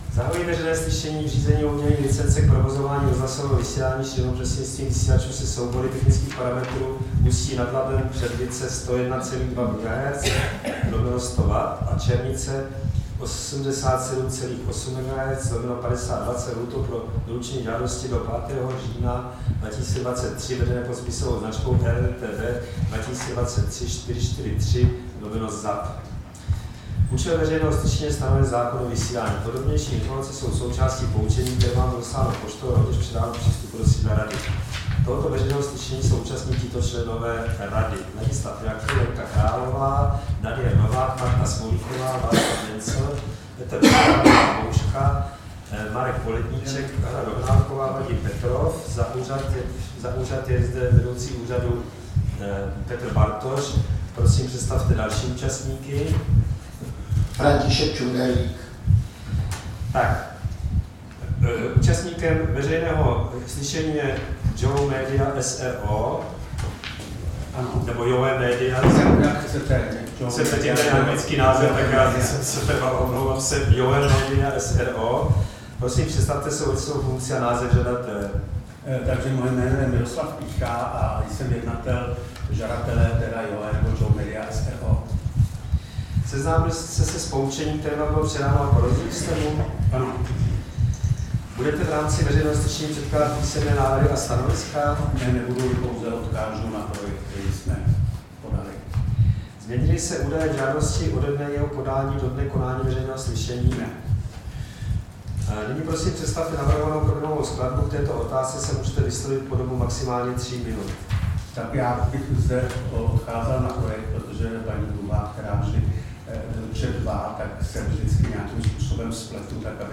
Veřejné slyšení v řízení o udělení licence k provozování rozhlasového vysílání šířeného prostřednictvím vysílačů se soubory technických parametrů Ústí nad Labem-Předlice 101,2 MHz/100 W a Plzeň-Černice 87,8 MHz/50 W
Místem konání veřejného slyšení je sídlo Rady pro rozhlasové a televizní vysílání, Škrétova 44/6, 120 00 Praha 2.
3. Veřejné slyšení zahajuje, řídí a ukončuje předseda Rady nebo jím pověřený člen Rady.